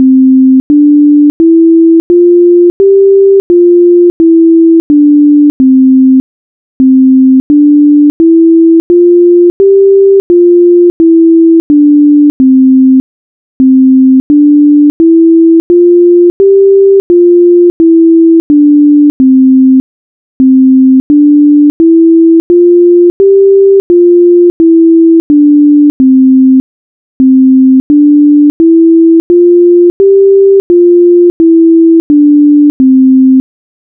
mamemimomu_C_Dur_maennlich
singing_exercise
MaMeMiMoMu_C_Dur_Maennlich.wav